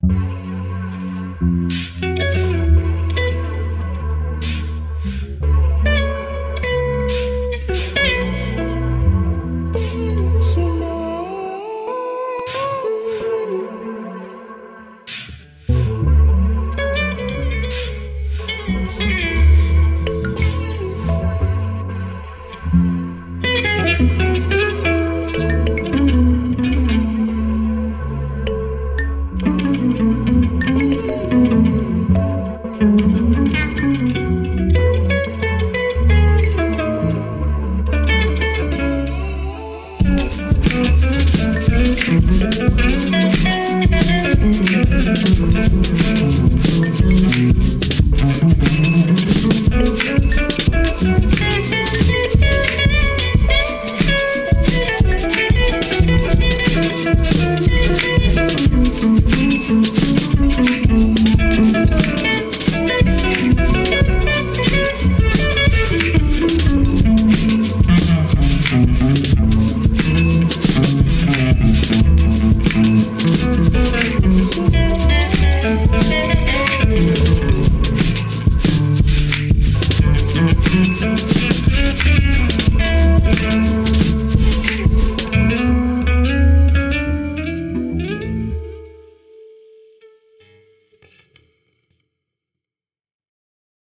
odetosomebodywelostsologuitarmix.mp3